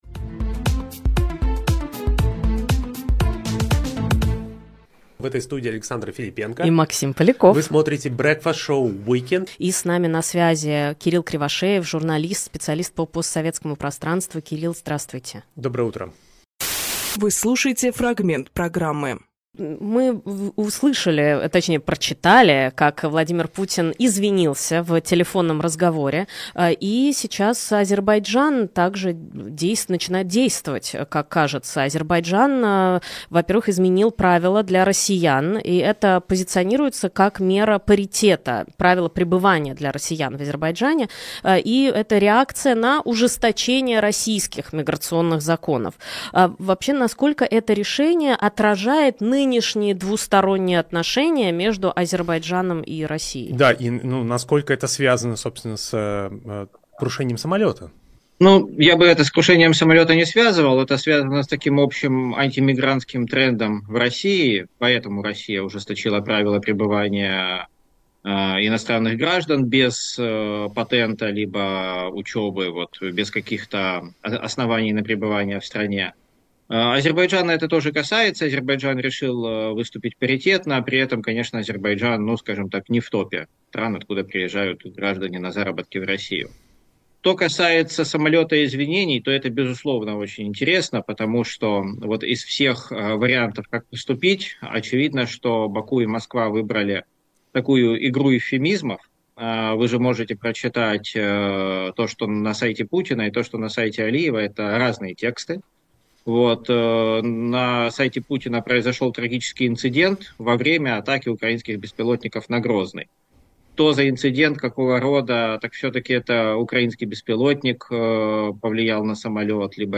Фрагмент эфира от 29.12.24